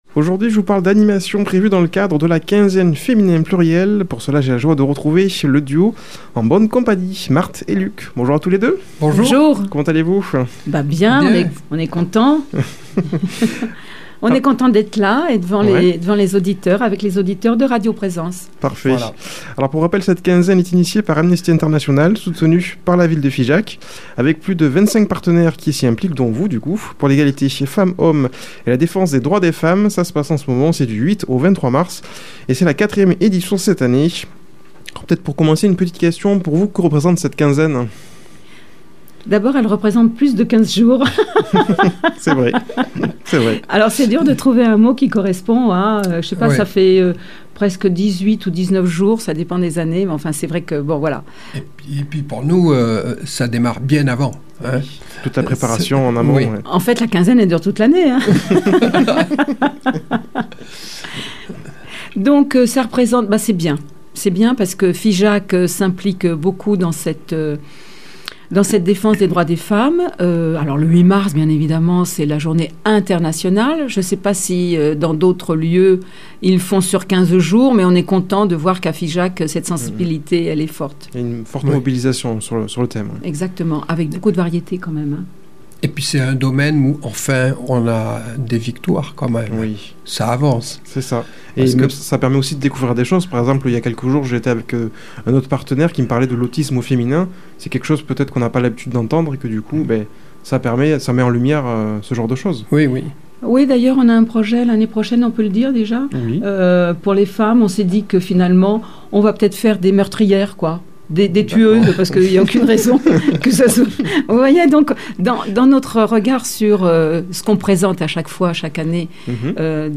Une émission présentée par
Présentateur